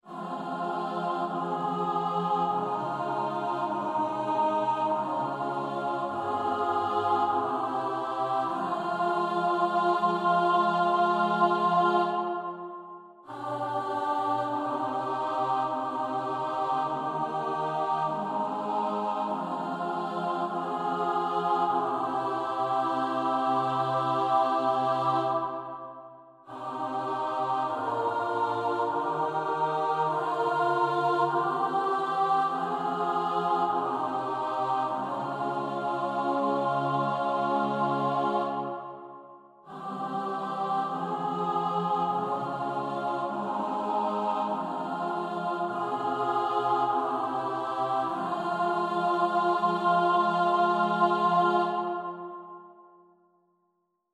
4-Part Chorales that aren’t Bach
Comments: The first phrase contains a non-dominant 9th chord. The second phrase ends with a picardy third. The third phrase ends with an interesting deceptive cadence of v to VI as the piece transitions back to f minor.